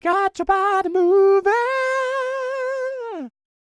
DD FALSET016.wav